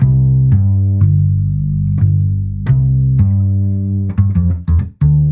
chillout " Jazz Bass B 1
描述：爵士乐，音乐，爵士乐